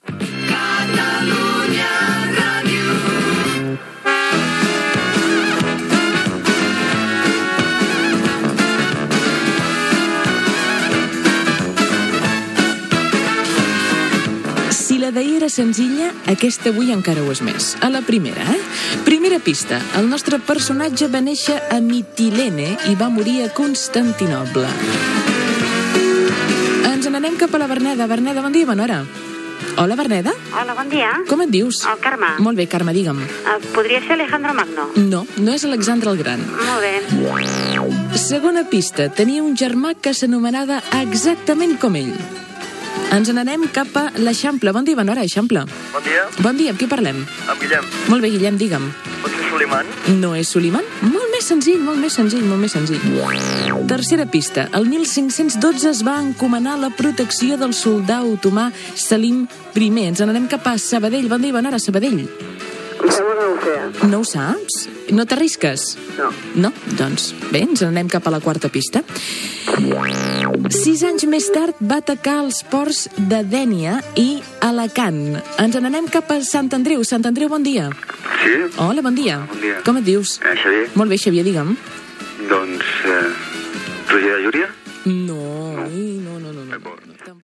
Indiactiu de la ràdio i trucades telefòniques per endevinar el personatge amagat del qual es van donant pistes
Entreteniment